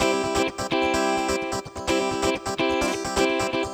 VEH3 Electric Guitar Kit 1 128BPM